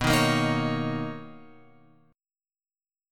B Major 9th